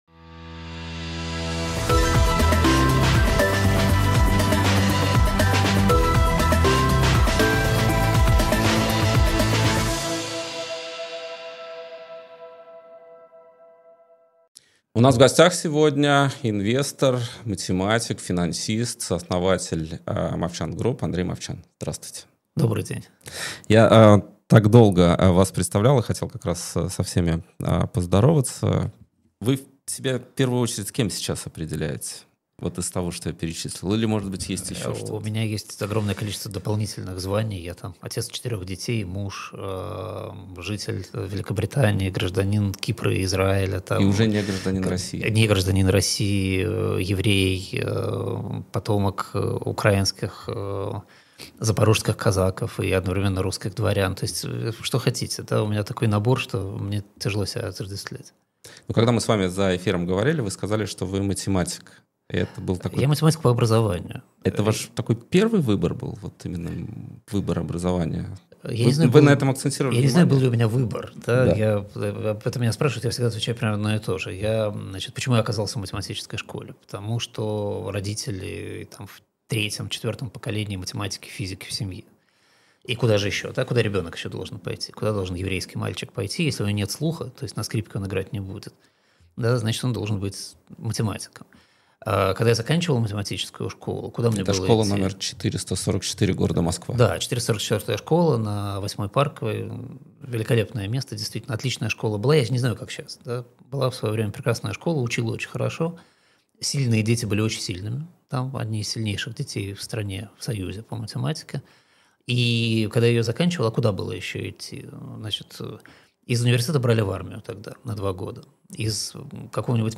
Интервью
Андрей Мовчан экономист